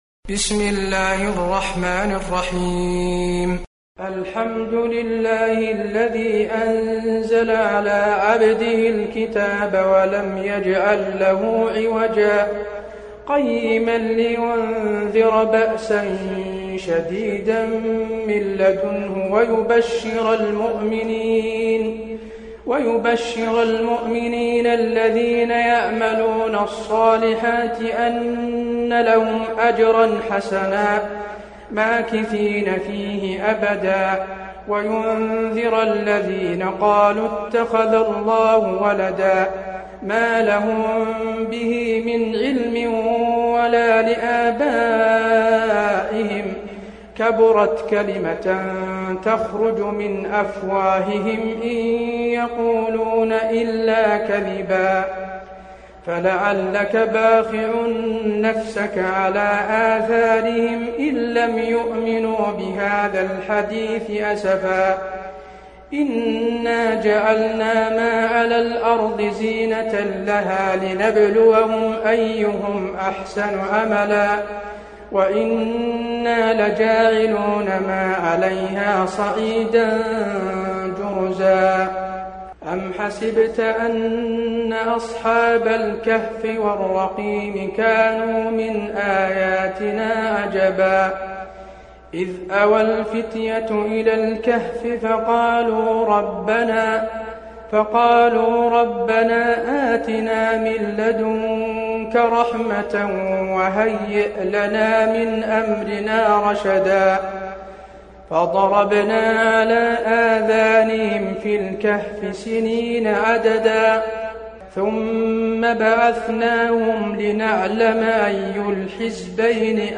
المكان: المسجد النبوي الكهف